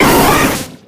Cries
WEEPINBELL.ogg